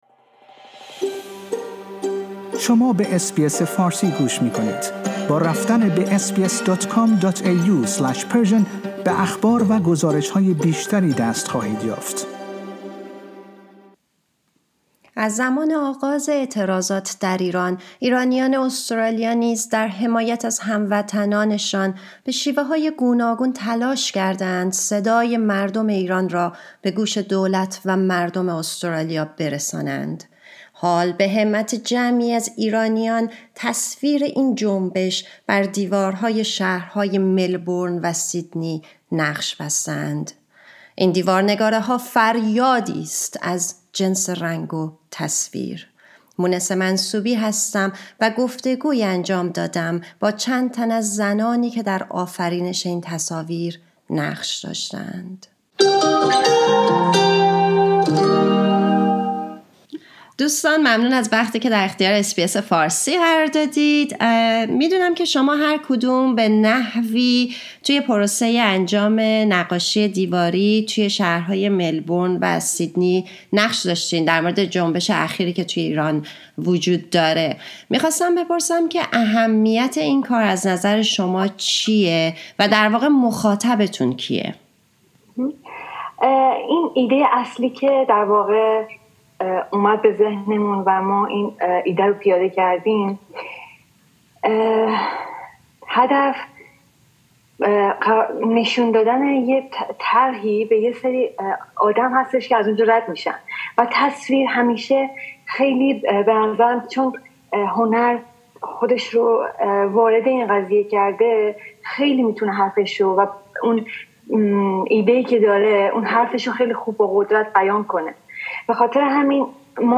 در این پادکست، اس بی اس فارسی گفتگویی صمیمی انجام داده با چند تن از زنانی که در آفرینش این تصاویر نقش داشتند.